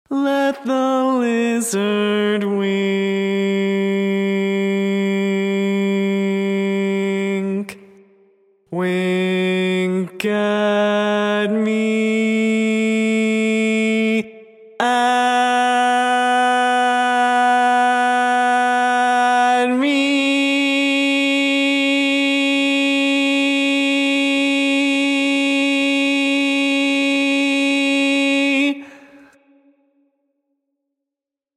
Key written in: C# Major
Type: Barbershop
Each recording below is single part only.